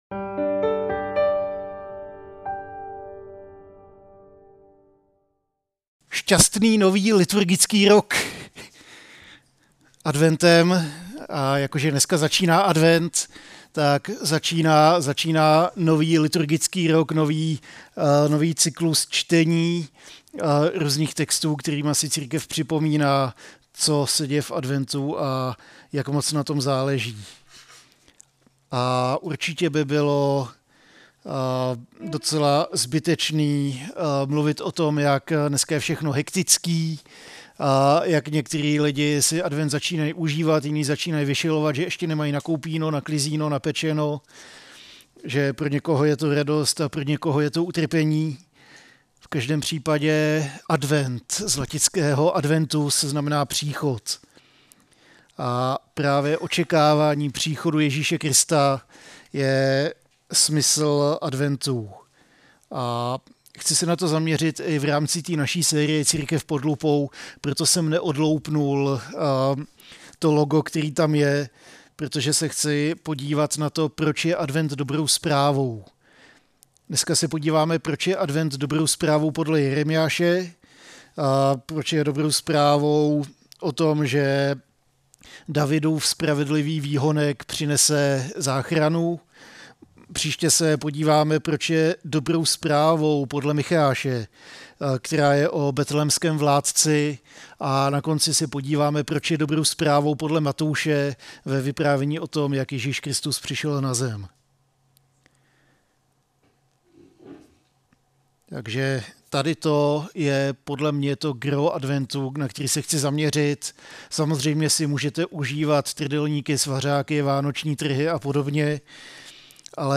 A kázání od série "Advent."